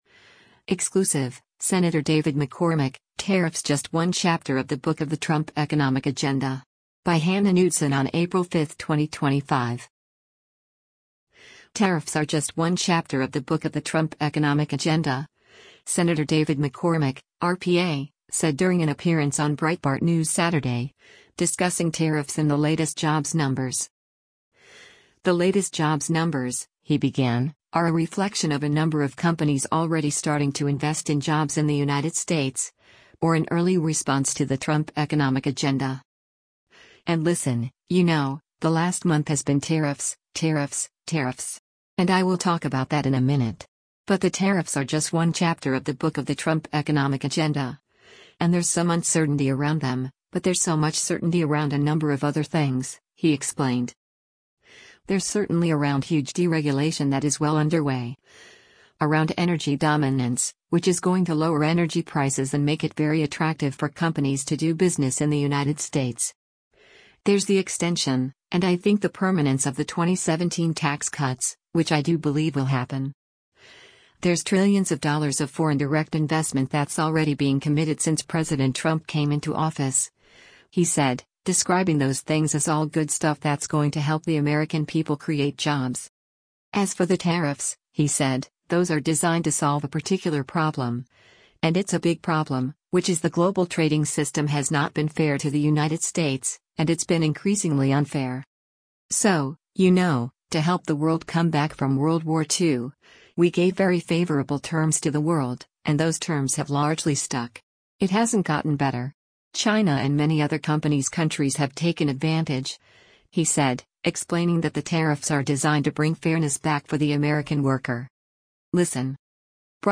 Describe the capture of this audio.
Breitbart News Saturday airs on SiriusXM Patriot 125 from 10:00 a.m. to 1:00 p.m. Eastern.